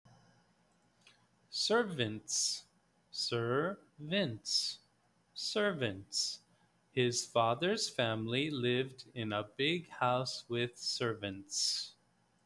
Read the word, study the definition, and listen to how the word is pronounced. Then, listen to how it is used in the sample sentences.